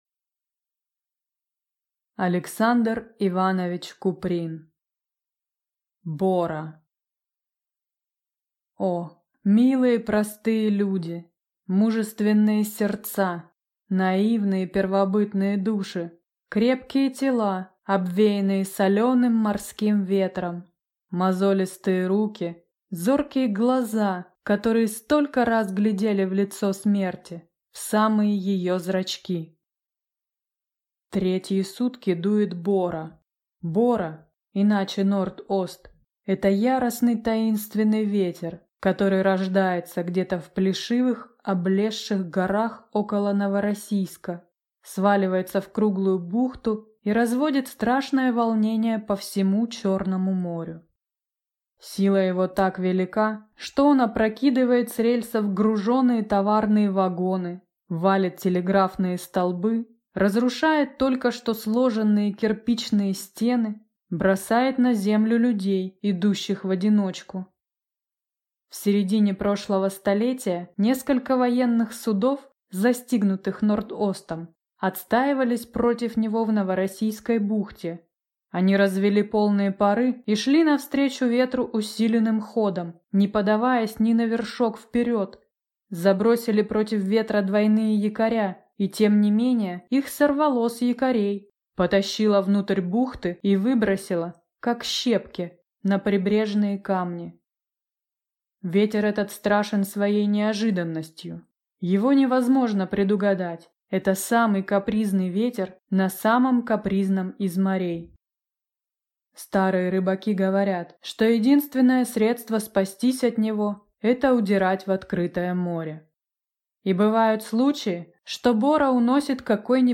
Аудиокнига Бора | Библиотека аудиокниг